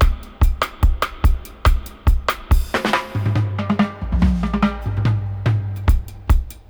142-FX-01.wav